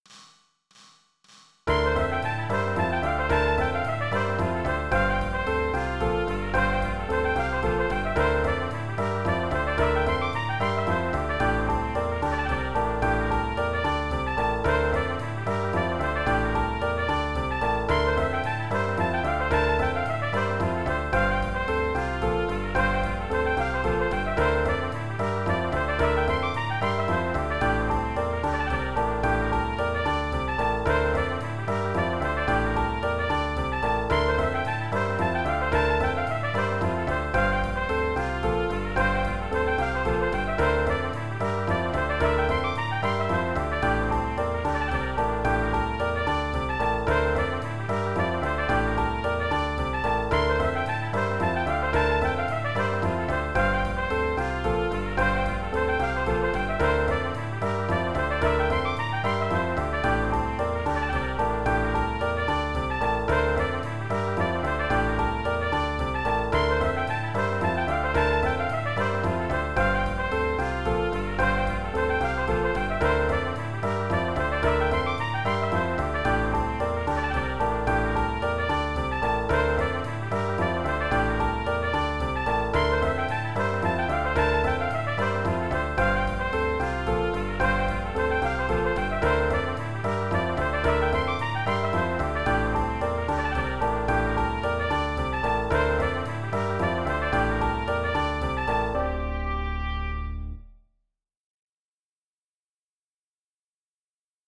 I later added bass and drums.